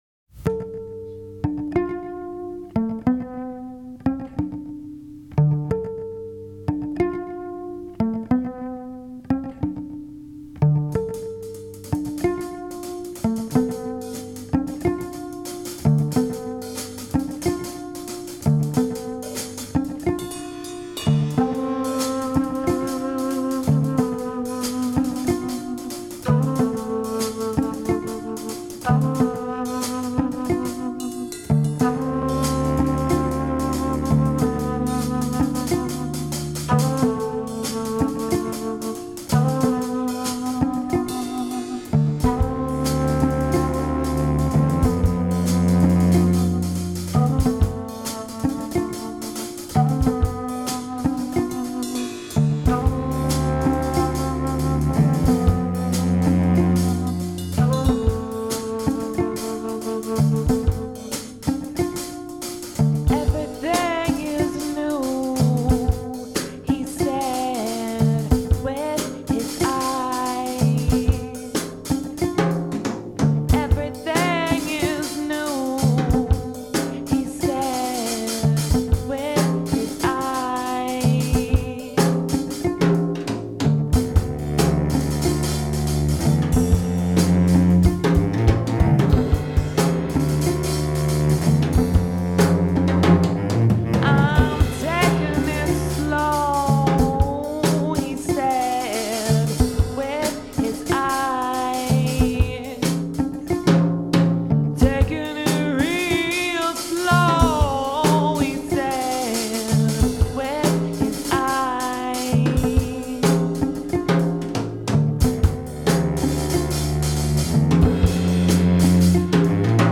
an occasional trio
flute, vocals
drums
cello, electronics
recorded live in Brighton